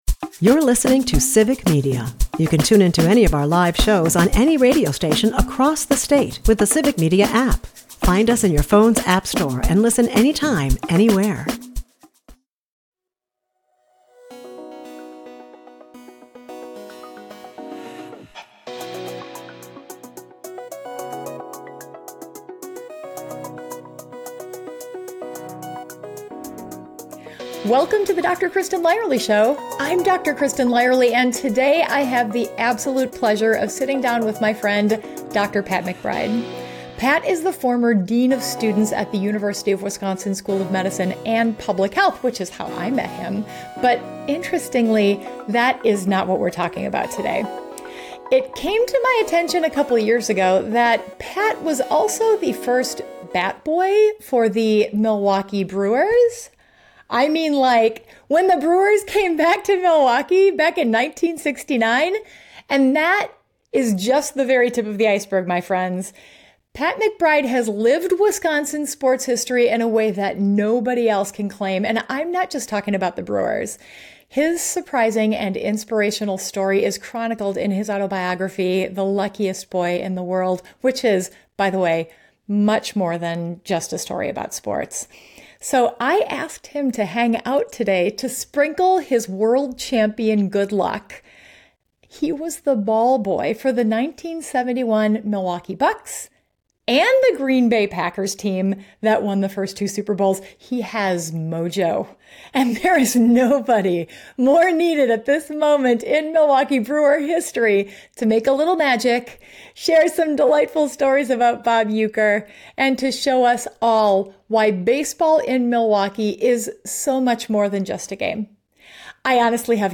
recorded on the day of Game 1 of the National League Championship Series